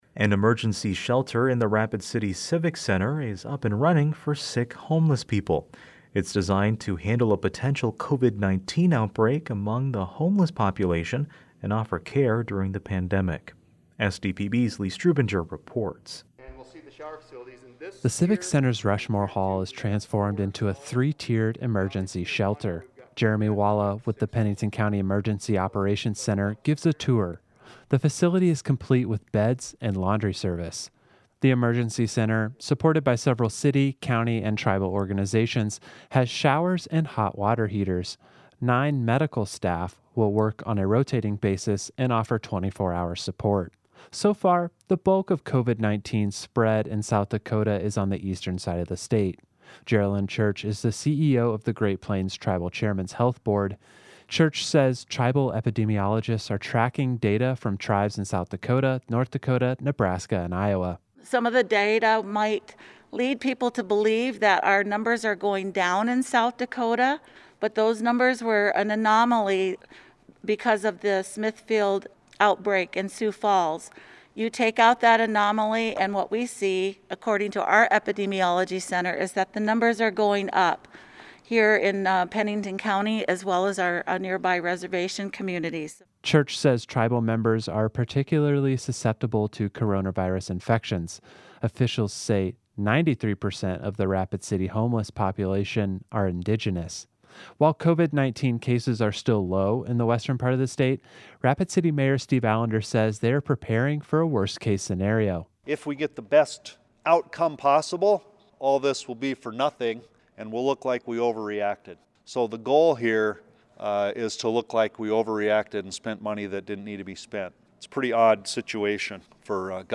The Civic Center’s Rushmore Hall is transformed into a three-tiered emergency shelter.